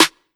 Metro Soft Snare.wav